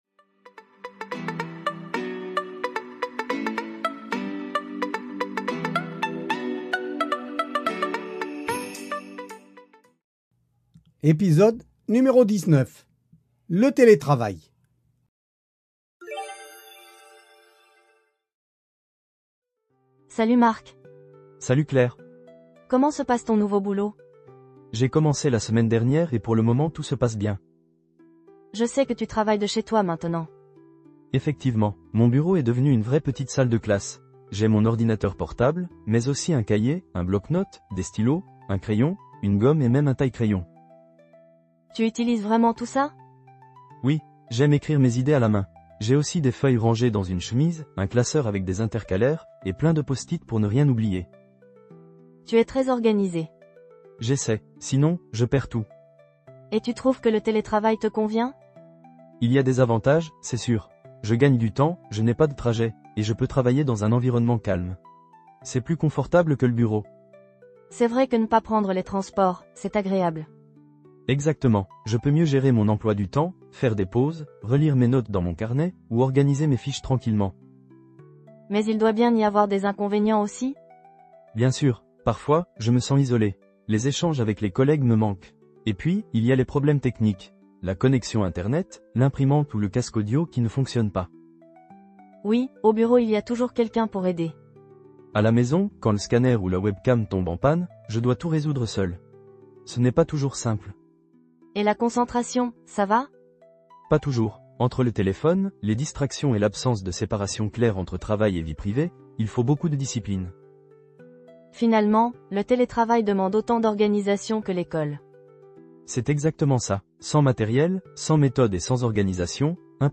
Voici un dialogue pour les élèves de niveau A1 sur une conversation entre deux amis qui parlent du télétravail.